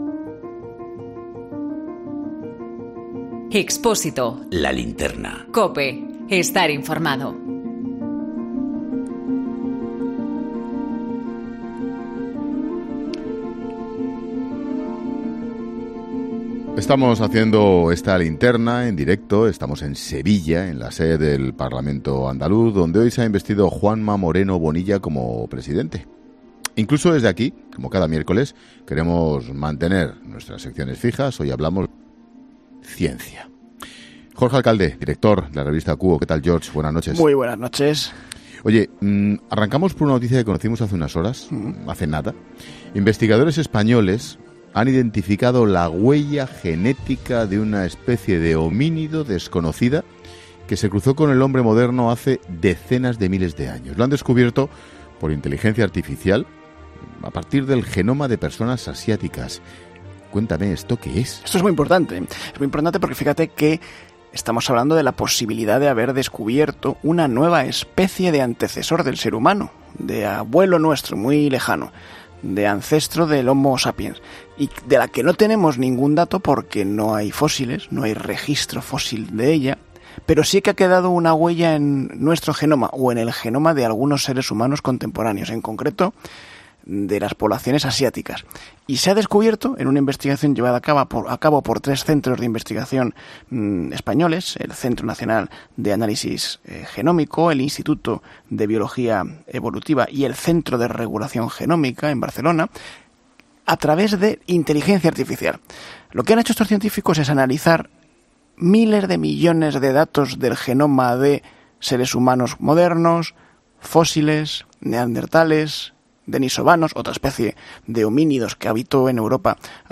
Hoy estamos en Andalucía, hacemos el programa desde el Parlamento que hoy ha investido a Juanma Moreno como presidente... pero incluso desde aquí, como cada miércoles, queremos seguir hablando de ciencia.